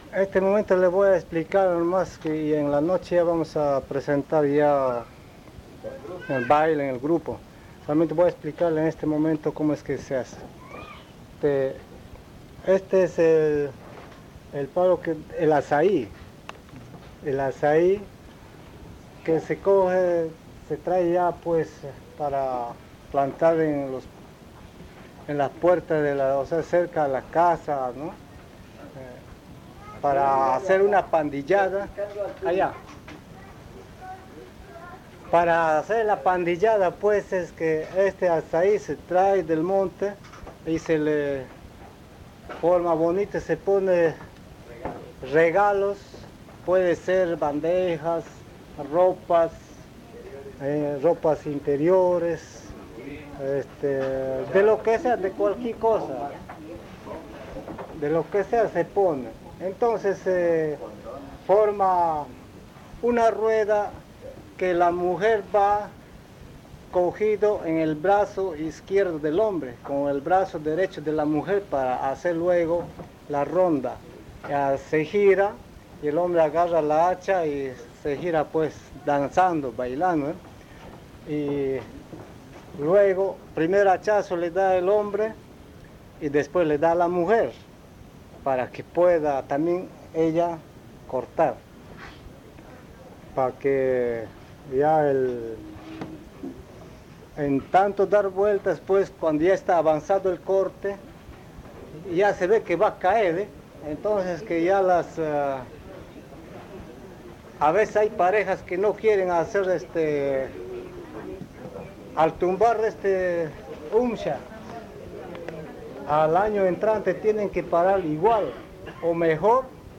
San Juan del Socó, río Loretoyacu, Amazonas (Colombia)